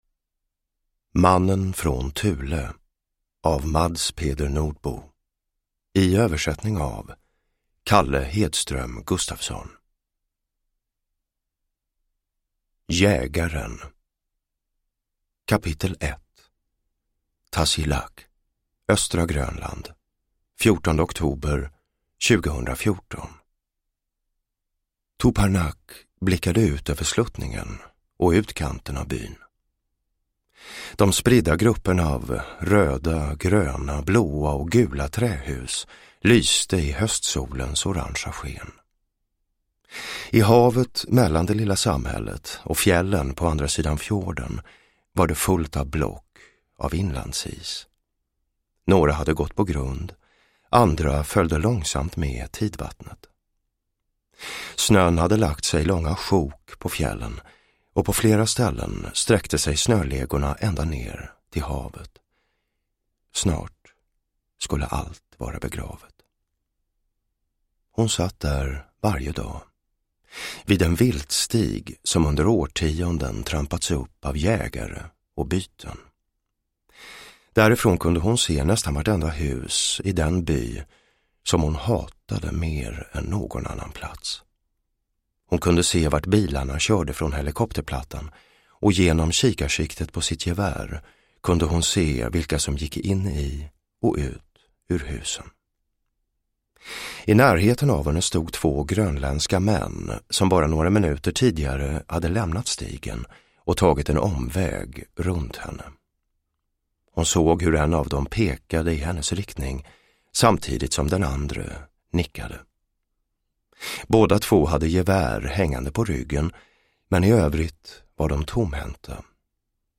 Mannen från Thule – Ljudbok – Laddas ner
Uppläsare: Jonas Malmsjö